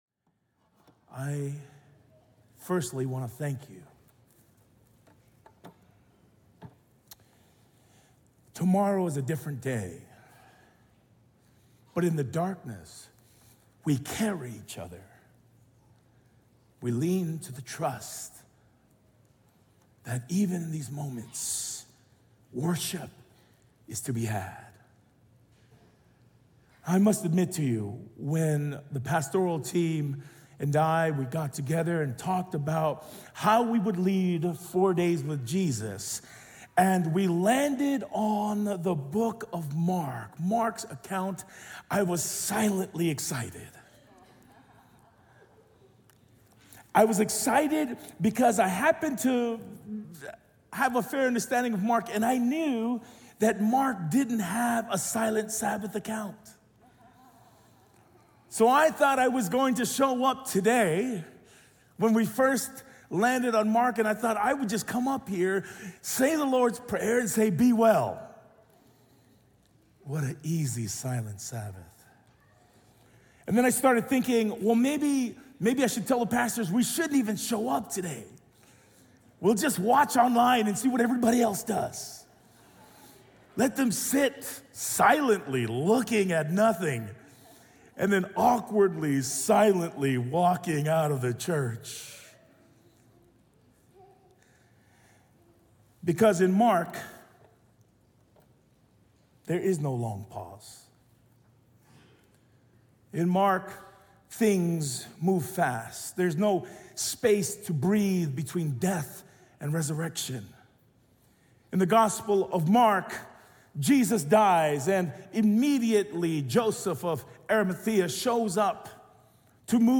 From Series: "Standalone Sermons"